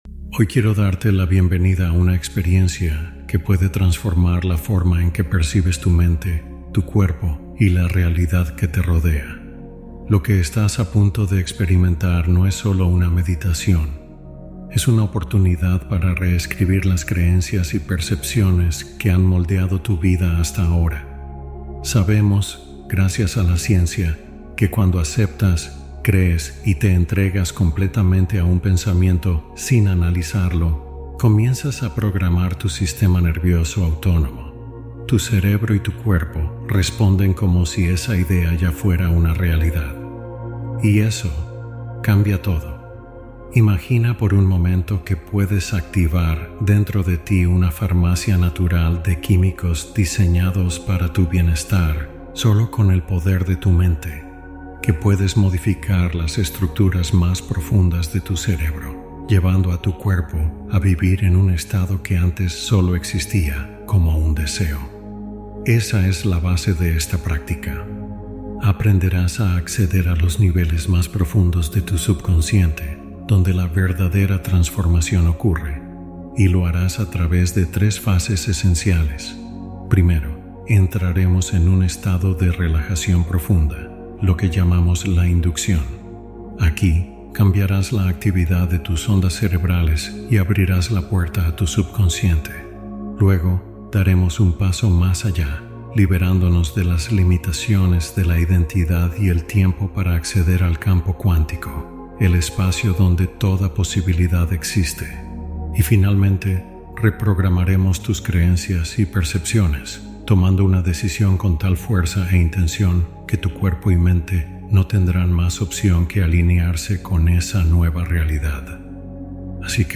Meditación de exploración del campo cuántico desde la experiencia interna